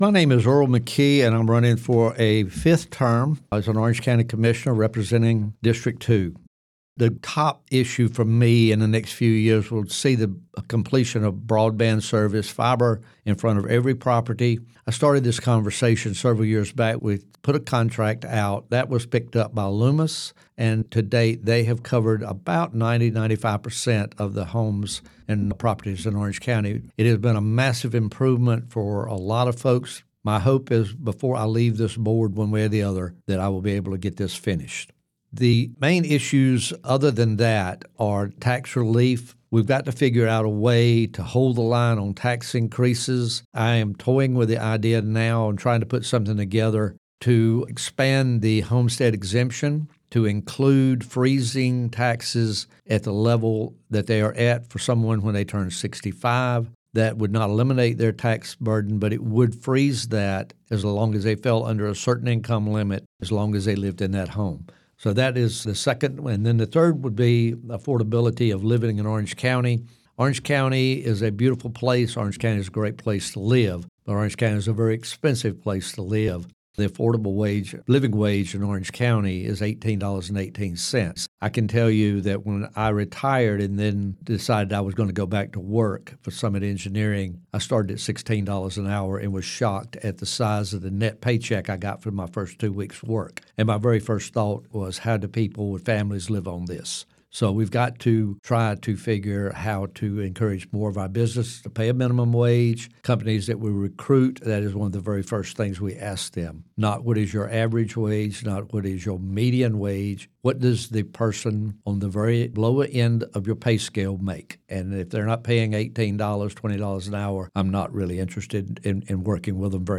97.9 The Hill spoke with each Democrat candidate, asking these questions that are reflected in the recorded responses: